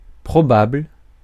Ääntäminen
France: IPA: [pʁɔ.ba.bl(ə)]